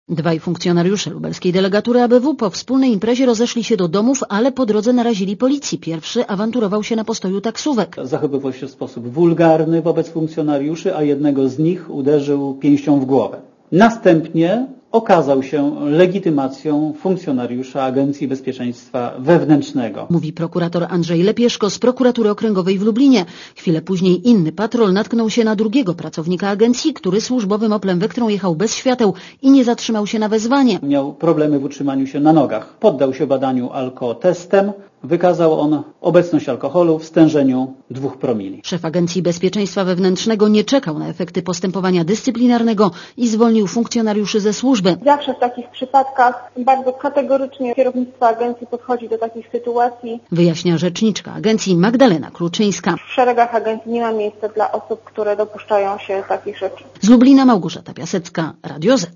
Posłuchj relacji reporterki Radia Zet (230 KB)